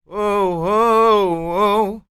Blues Soul